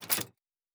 Locker 10.wav